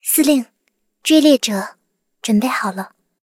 追猎者编入语音.OGG